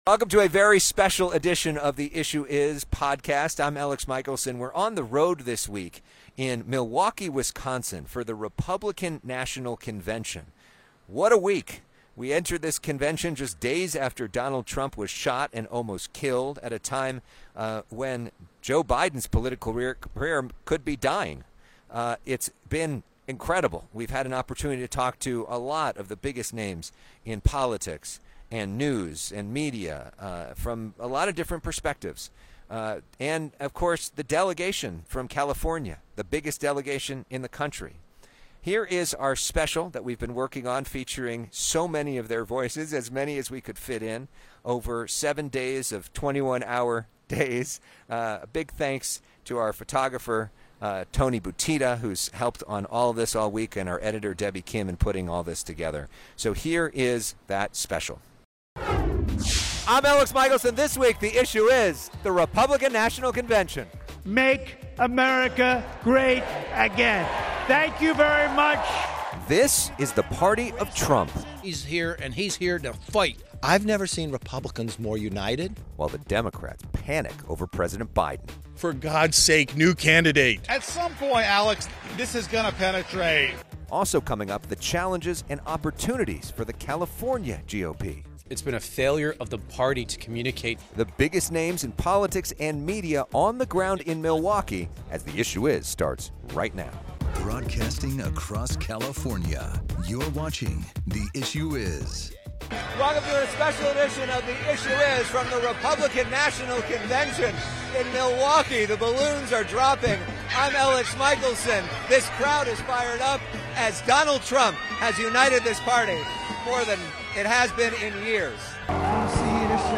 An unprecedented week in American politics, as "The Issue Is" hits the road for the Republican National Convention.